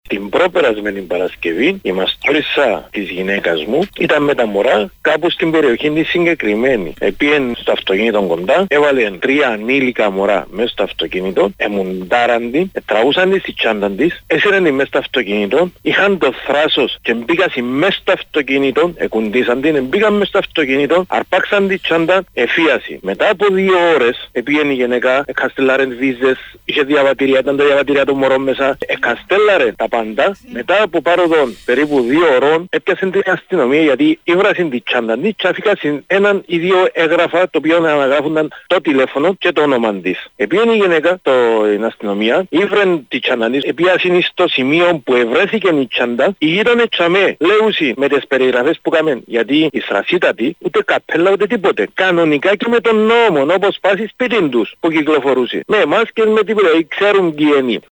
Σε πολύ σοβαρές καταγγελίες για ομάδα νεαρών, που εδώ και καιρό λυμαίνεται την πρώην Τουρκοκυπριακή Συνοικία στη Λεμεσό, μπουκάροντας σε σπίτια και αυτοκίνητα προτάσσοντας μαχαίρια και ληστεύοντας με θράσος και αγριότητα περίοικους και περαστικούς, προέβησαν πολίτες στην πρωινή ενημερωτική εκπομπή στο Κανάλι 6.
Στα πιο κάτω ηχητικά οι καταγγελίες των πολιτών